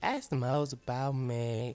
Southside Vox (14).wav